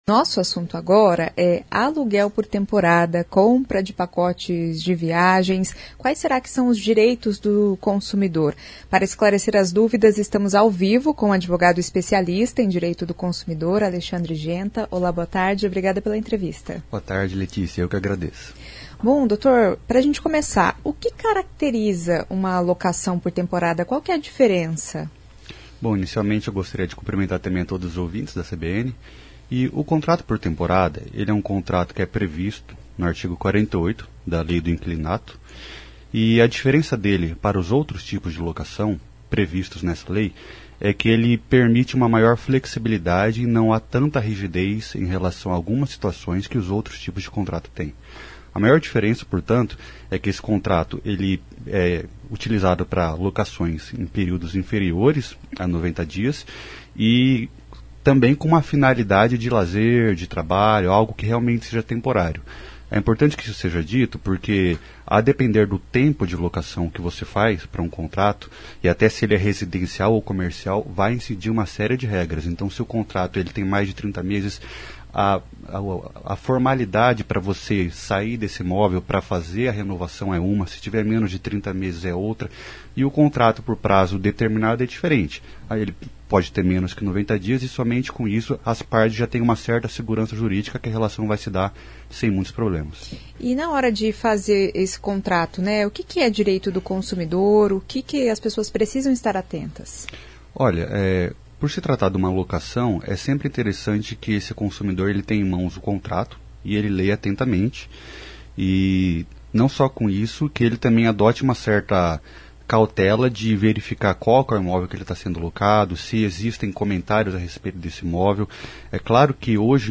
O advogado especialista em direito do consumidor, fala sobre os direitos do consumidor em aluguel por temporada.